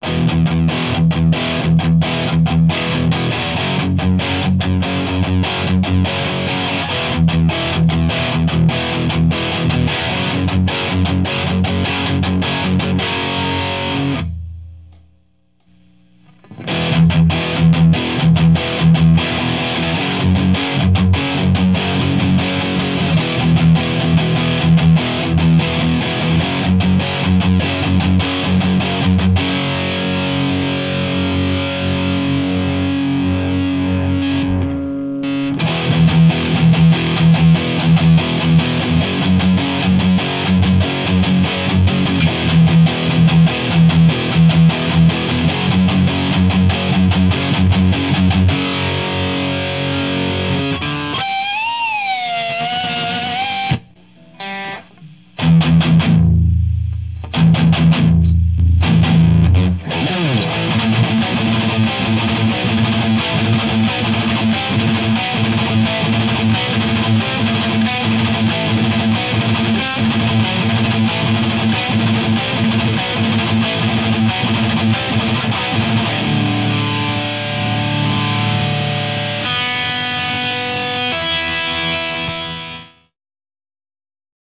いつもながらお粗末なサンプルで。。。今回はEMG89です。
今回は、ゲイン5　トーンシフトオン
MTRはMRS-8を使いました。マイクはSM57
ベリンガーでのブースト
ベリンガーは音をそのままブーストしてるだけなので、ザックドライブは歪みをプラスしているので音が違いますね！